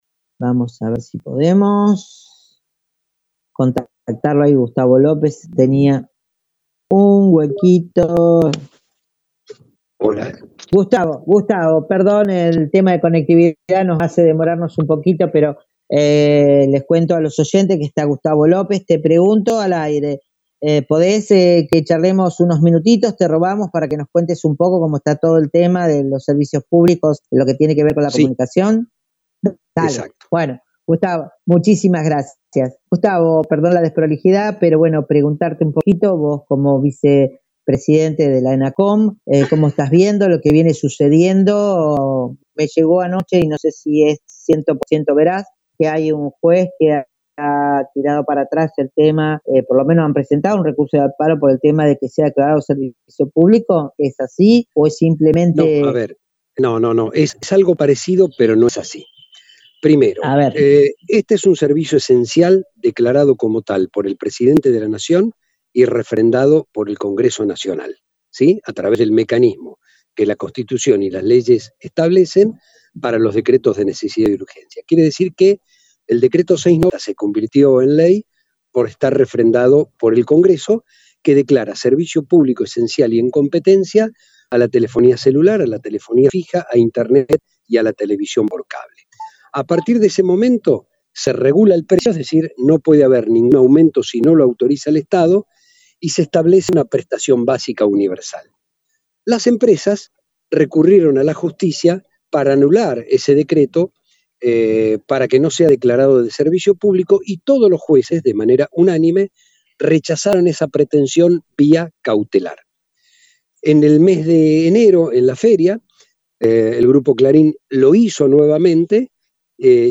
Entrevista al Vice Presidente de ela ENACOM Gustavo López sobre cobro indebido de las empresas de TV por Cable
En el Programa «Imaginario Popular» en Radio Gran Rosario Entrevista con el  Vice Presidente de la ENACOM Gustavo López sobre el aumento indebido de las empresas de TV x Cable considerando que han sido declarados servicios públicos tanto a las empresas de telefonía, internet  y de TV de Cable.